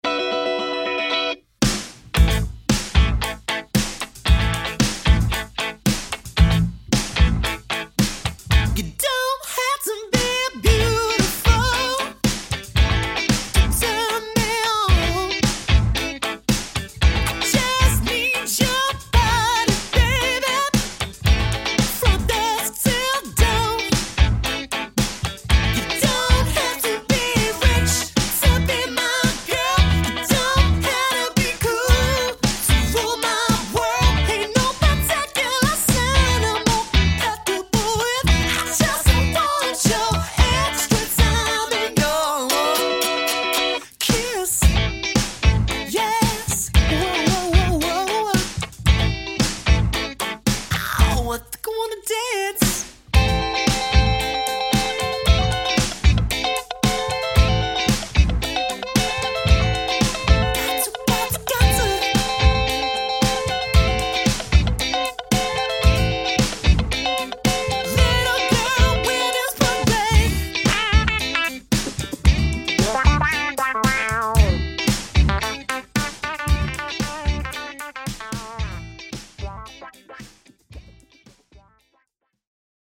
• 4-piece
Vocals / Guitar, Bass, Guitar, Drums